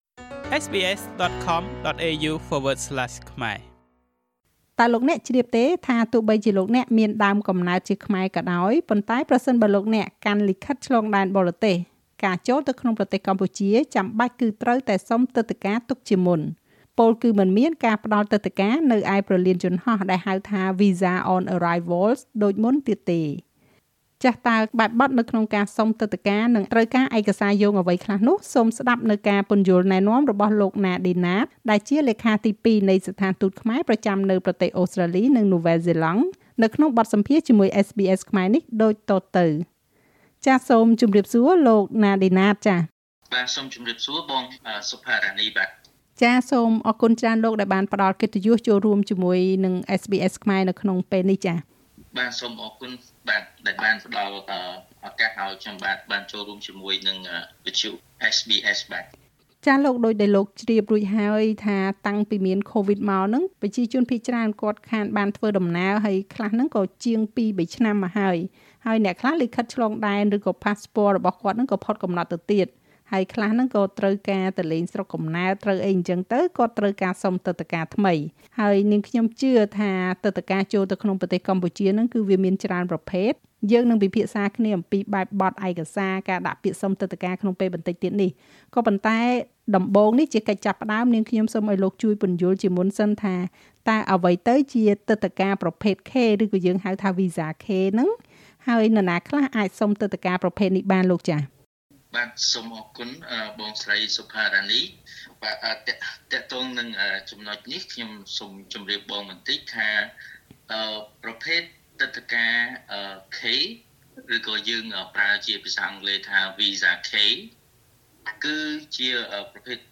នៅក្នុងបទសម្ភាសន៍ជាមួយ SBS ខ្មែរដូចតទៅ។